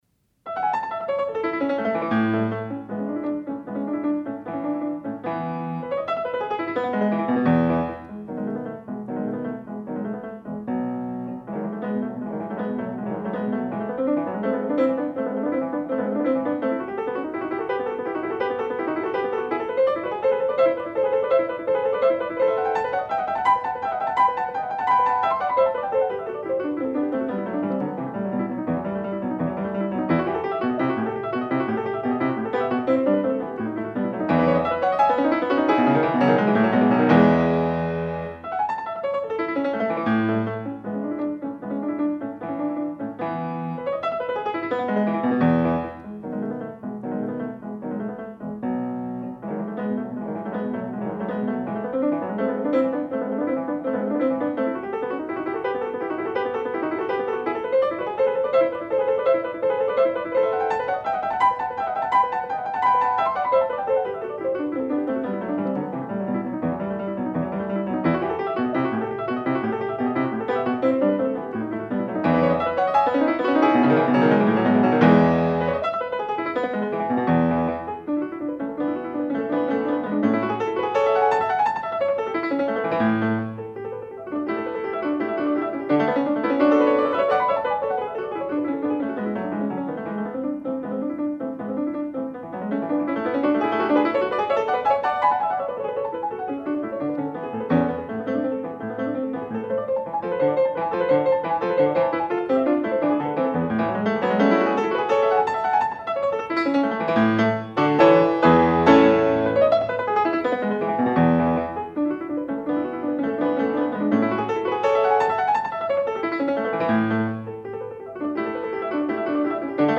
Сонаты для фортепиано.